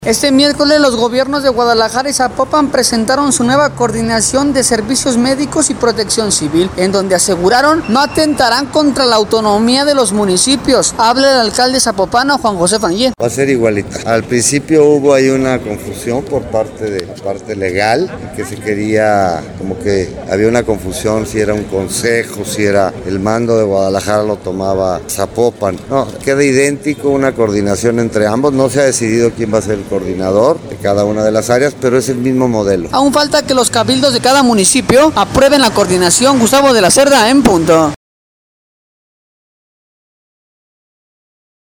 Este miércoles, los Gobierno de Guadalajara y el de Zapopan, presentaron su nueva coordinación de Servicios Médicos y Protección Civil, en donde aseguraron que no atentarán contra la autonomía de los municipios. Habla el alcalde zapopano, Juan José Frangie: